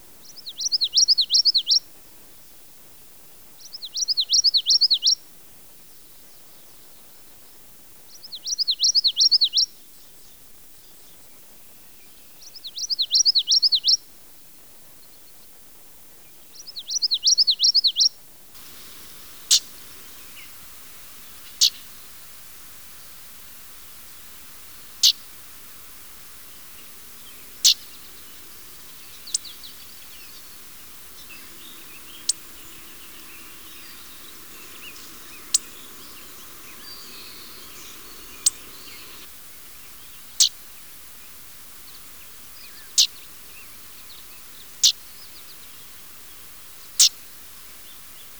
"Common Yellowthroat "
Geothlypis trichas
reinita-picatierra.wav